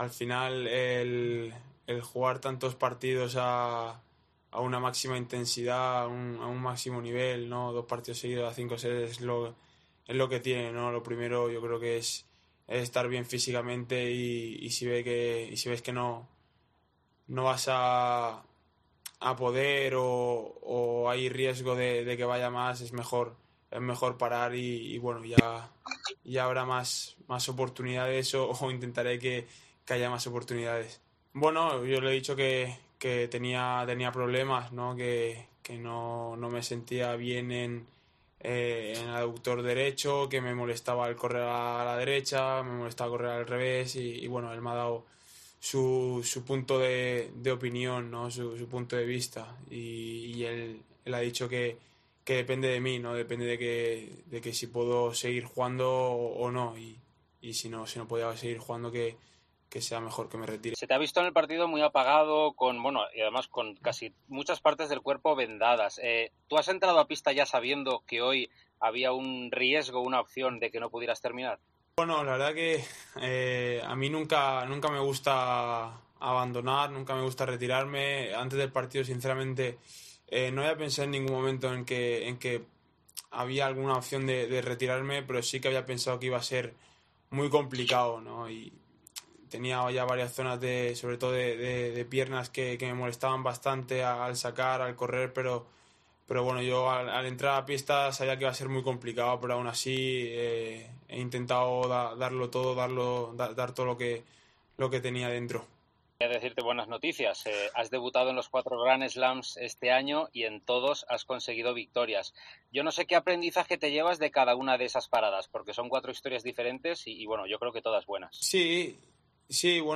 Carlos Alcaraz, en Eurosport, seguiré siendo el mismo chaval de siempre